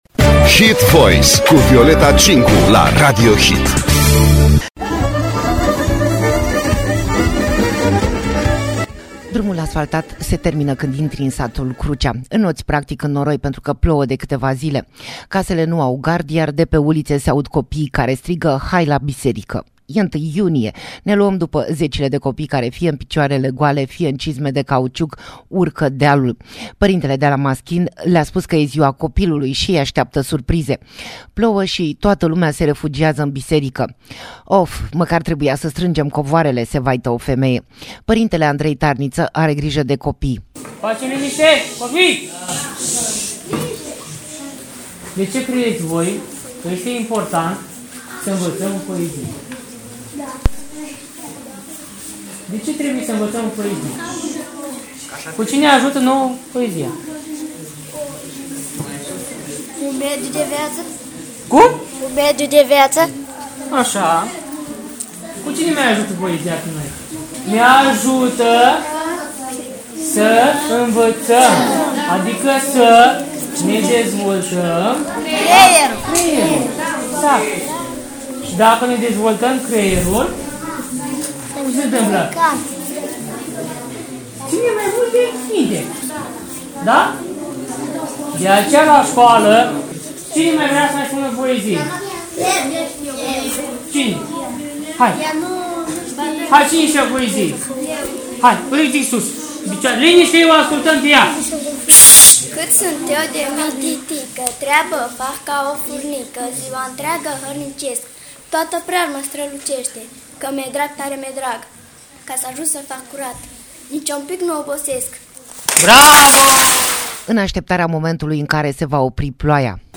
AUDIO Reportaj. Ziua Copilului la Crucea! Când nu știai că există 1 iunie!
Concertul Damian and Brothers i-a făcut pe copii să danseze și să cânte. În curtea bisericii, în noroi și bălți.